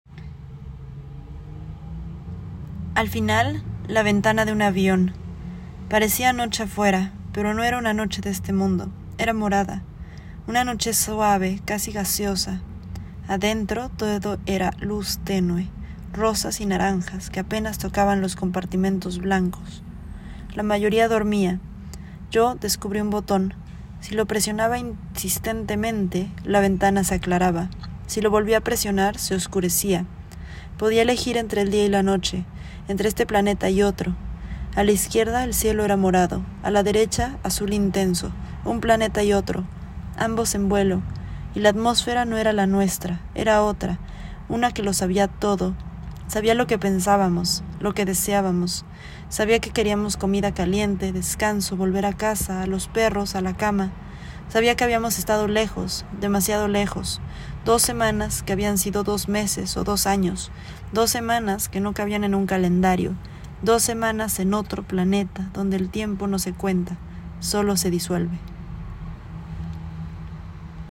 Cada fragmento fue escrito como un flujo de conciencia, grabado en audio con mi voz y acompañado por imágenes específicas, evocadas directamente por lo que se cuenta o añadidas por asociación libre.